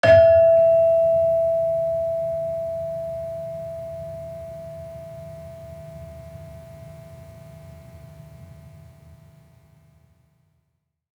Gamelan Sound Bank
Gender-3-E4-f.wav